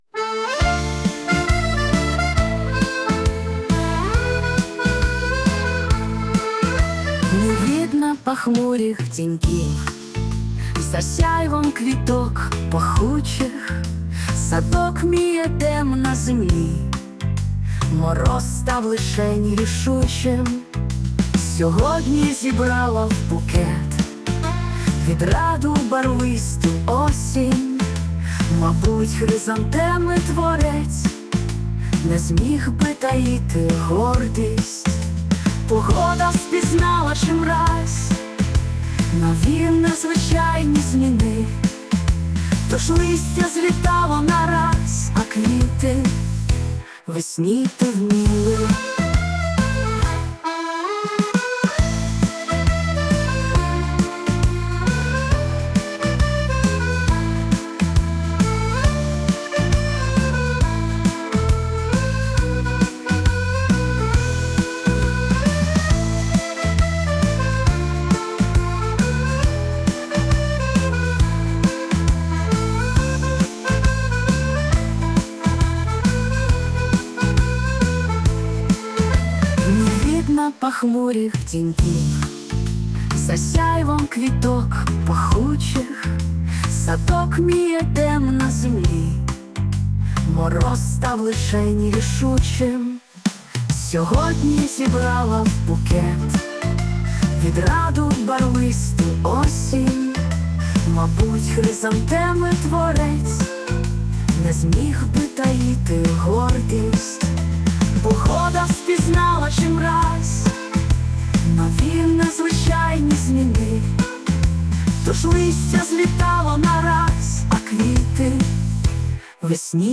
СТИЛЬОВІ ЖАНРИ: Ліричний